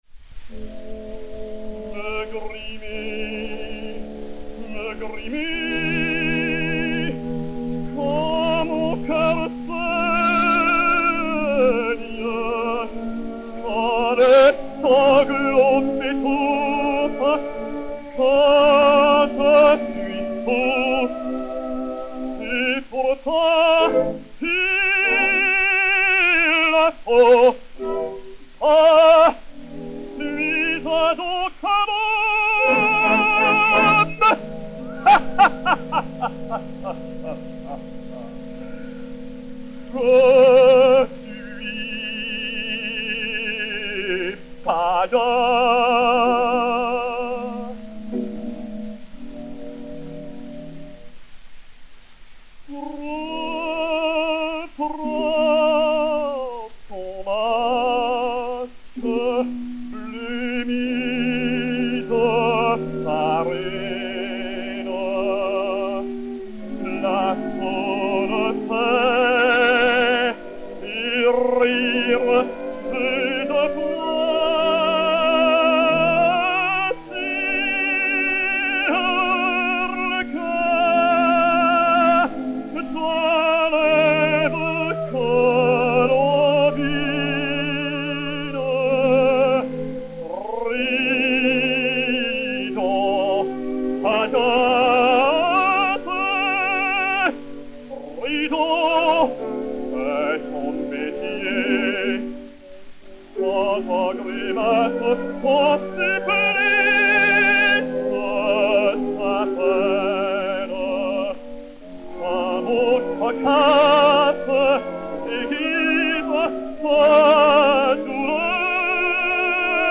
et Orchestre
XP 4540, enr. à Paris fin 1909